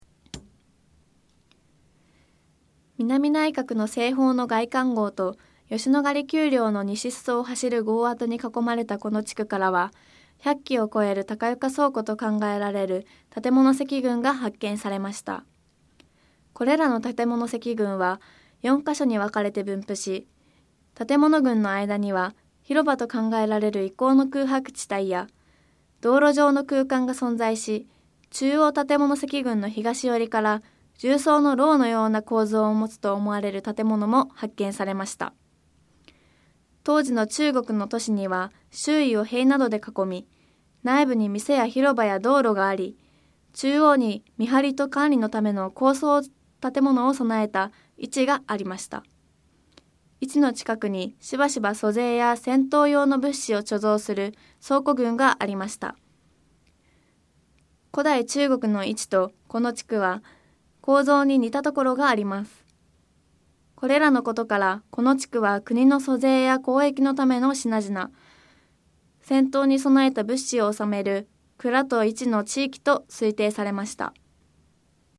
これらのことから、この地区はクニの租税や交易のための品々、戦闘に備えた物資を納める倉と市の地域と推定されました。 音声ガイド 前のページ 次のページ ケータイガイドトップへ (C)YOSHINOGARI HISTORICAL PARK